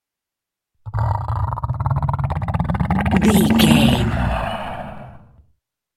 Monster growl snarl predator
Sound Effects
scary
ominous
angry